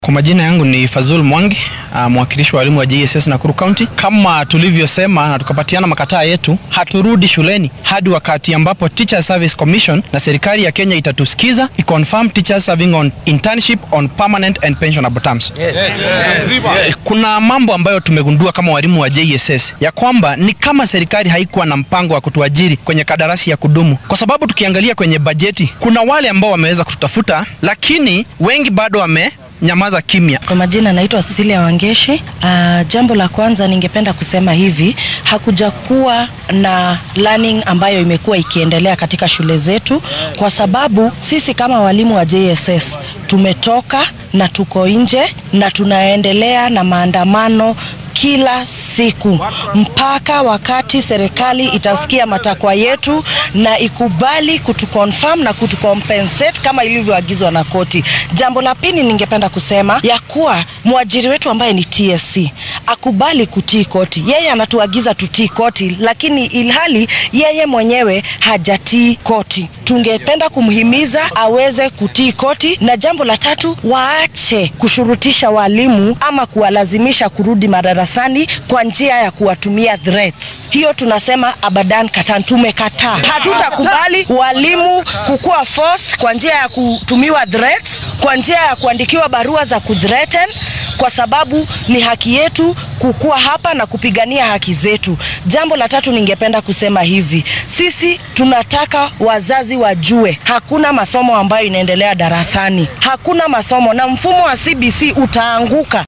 Qaar ka mid ah macallimiinta dibadbaxa ka dhigay Nakuru oo warbaahinta la hadlay ayaa wacad ku maray in aynan shaqo joojinta soo afjari doonin ilaa laga fulinaya dalabaadkooda.